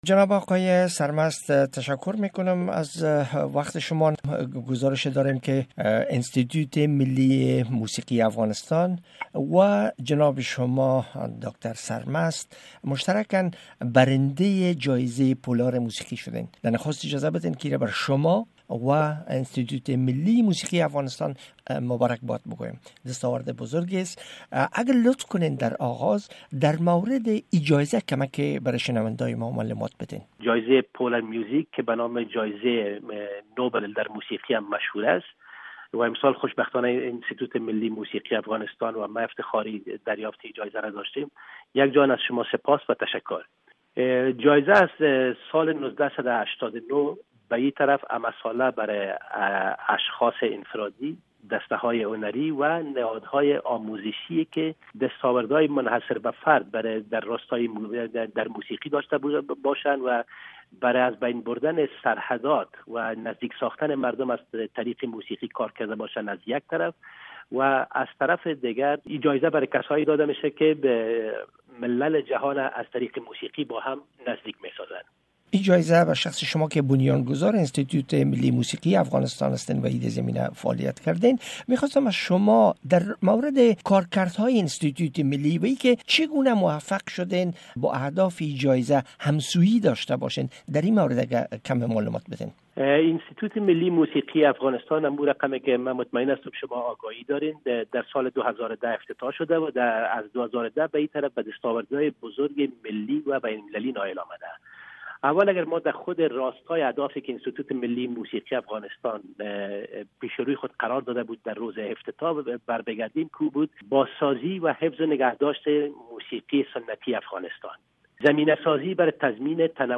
شرح کامل مصاحبه با احمد ناصر سرمست را از اینجا بشنوید: